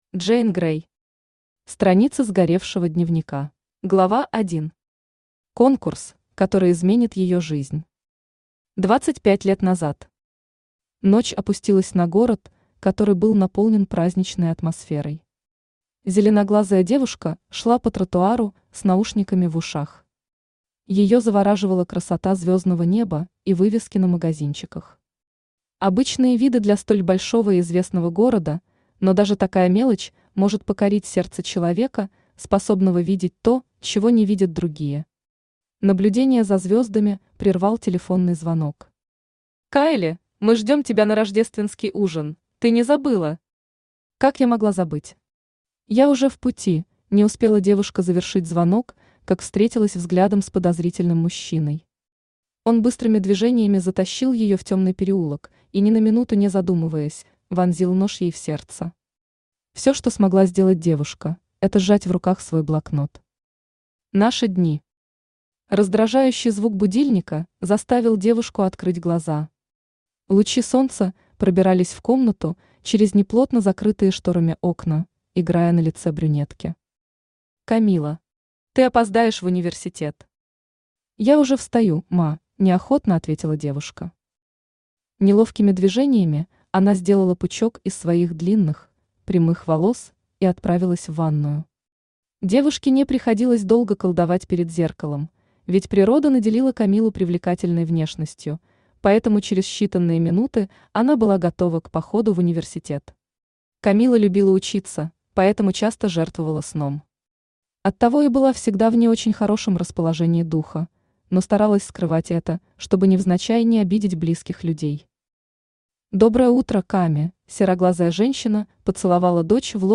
Аудиокнига Страницы сгоревшего дневника | Библиотека аудиокниг
Aудиокнига Страницы сгоревшего дневника Автор Джейн Грей Читает аудиокнигу Авточтец ЛитРес.